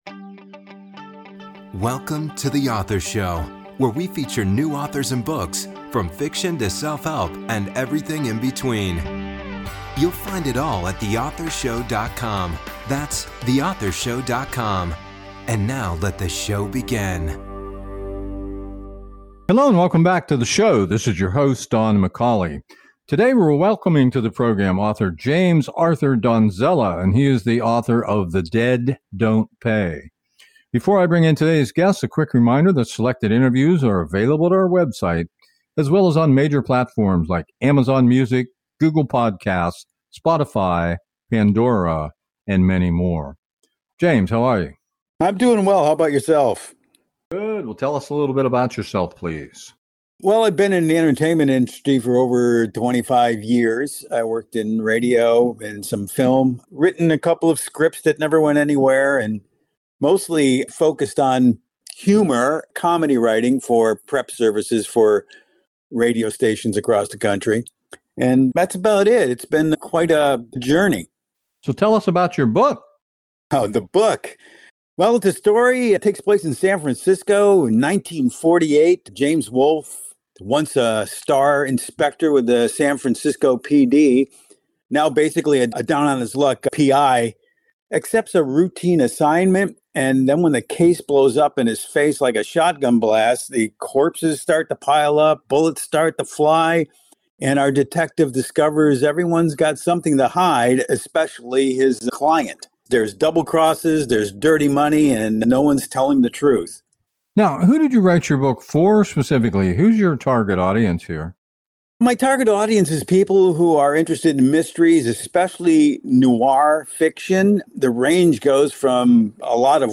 The Authors Show Interview